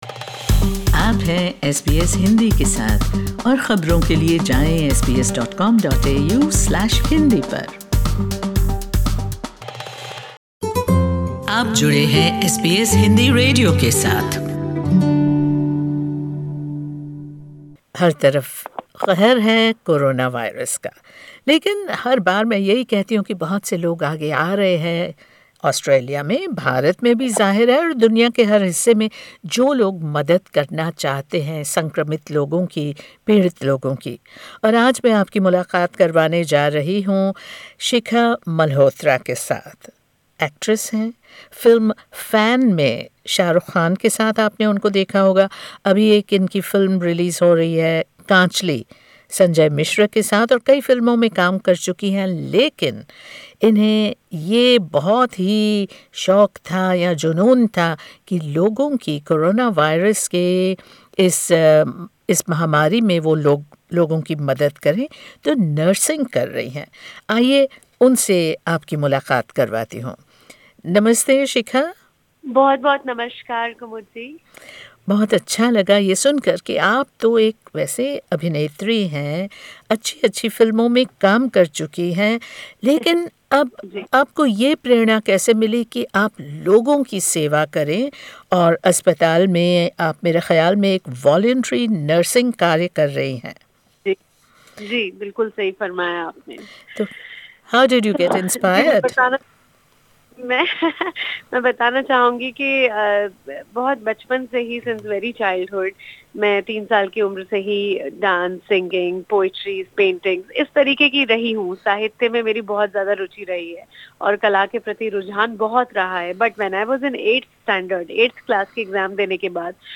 Her voice breaks down as she tells us about a seven-month-old baby who is COVID-19 positive and is in the hospital with his mother and grandmother, who are both COVID-19 patients.